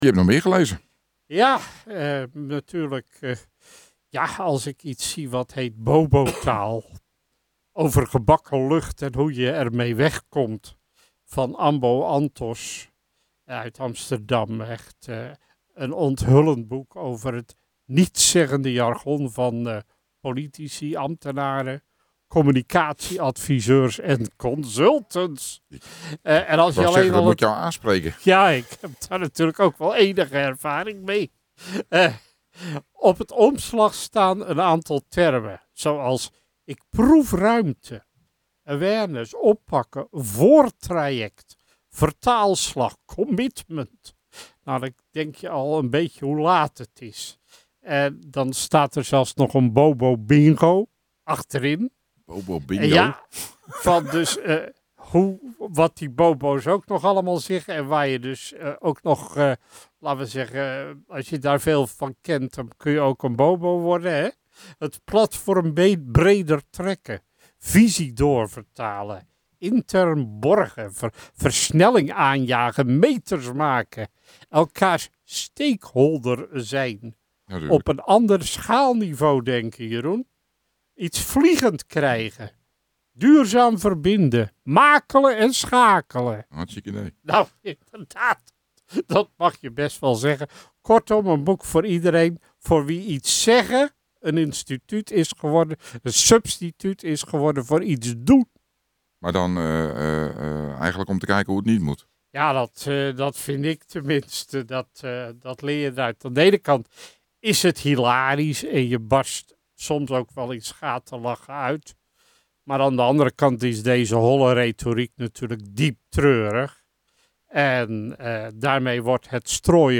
Het boek is tevens op 10 december 2014 besproken in het programma Puur Cultuur van MeerRadio.